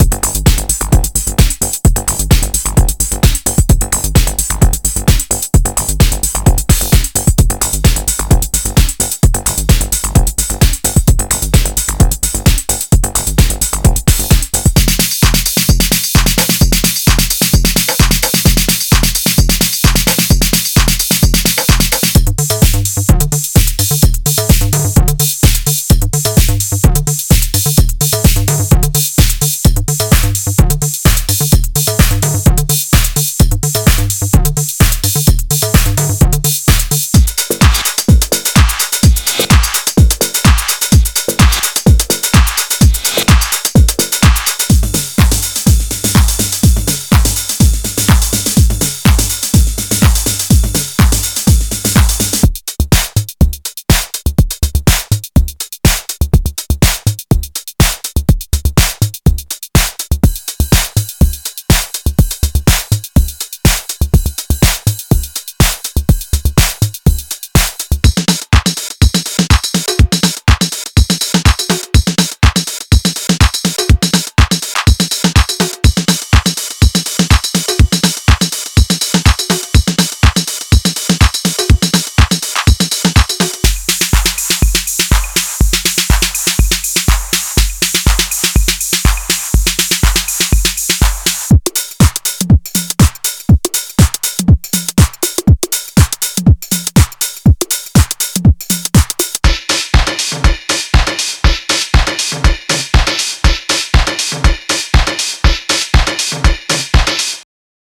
Genre:Techno
デモサウンドはコチラ↓
148 WAV Loops